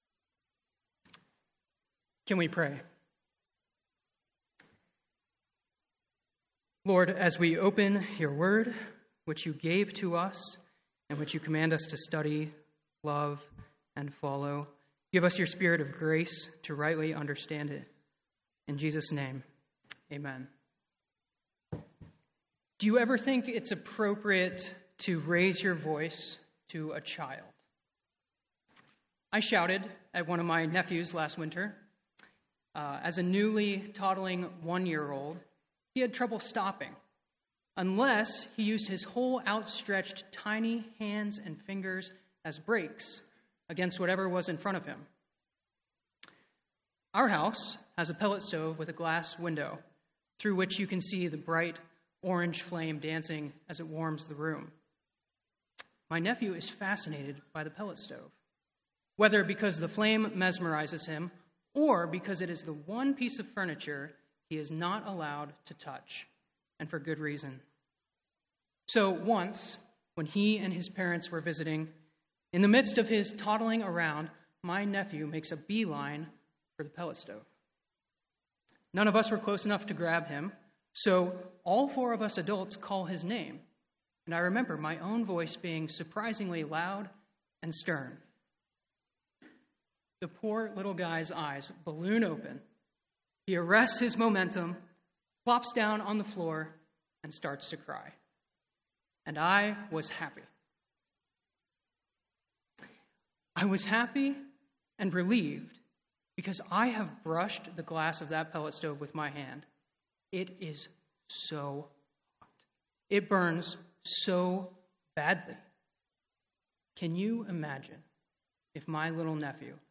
2022 Sermon Outline 1. God gave the Ten Commandments to reveal his character God's holiness God's goodness God's humble immanence 2.